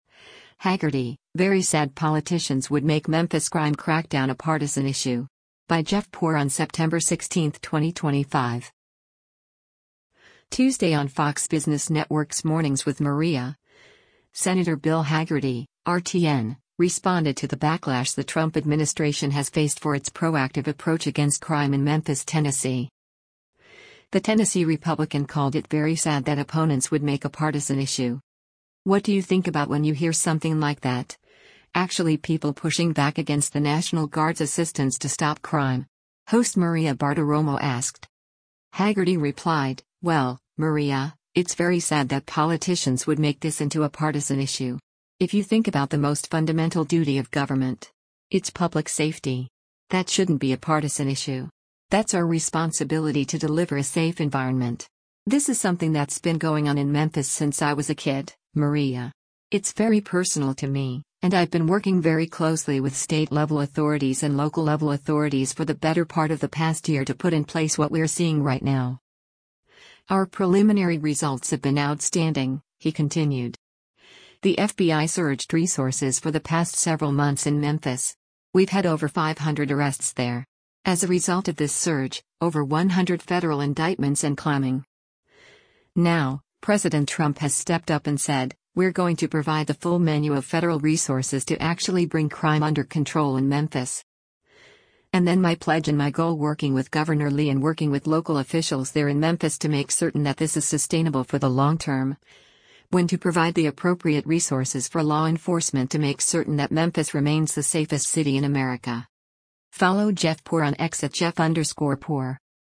Tuesday on Fox Business Network’s “Mornings with Maria,” Sen. Bill Hagerty (R-TN) responded to the backlash the Trump administration has faced for its proactive approach against crime in Memphis, TN.